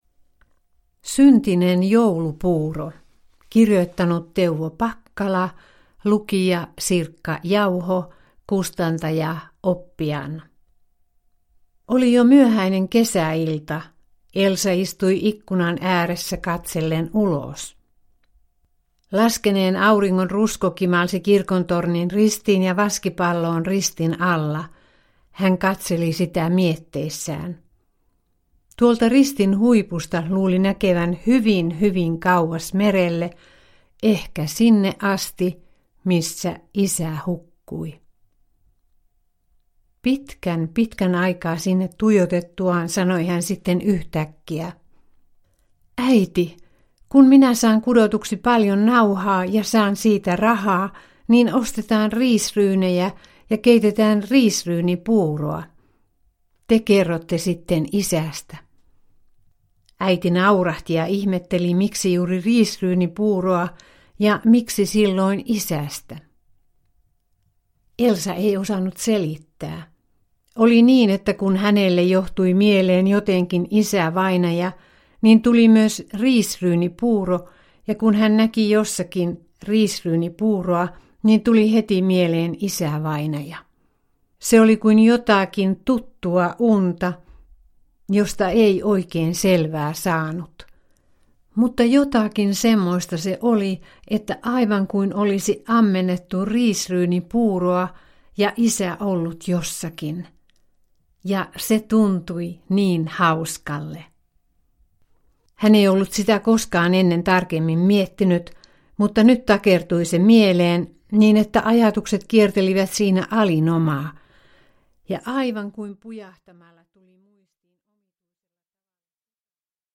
Syntinen joulupuuro – Ljudbok – Laddas ner